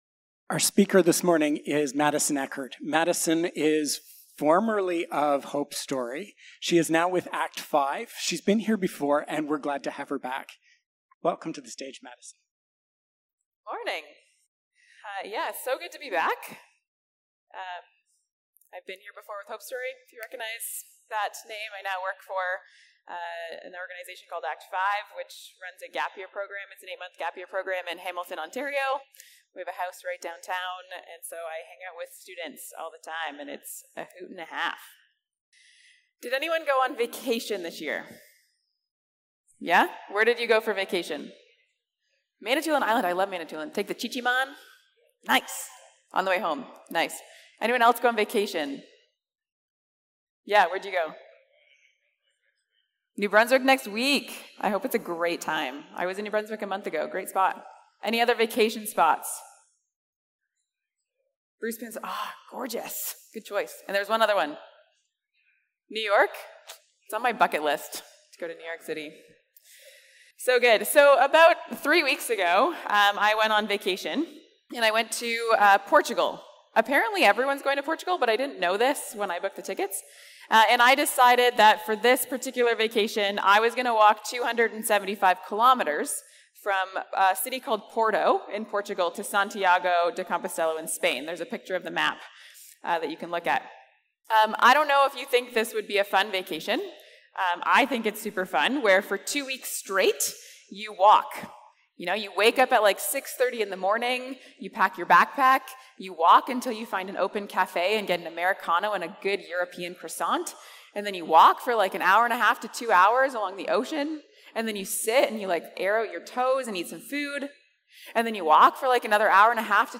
August-4-Sermon-1.mp3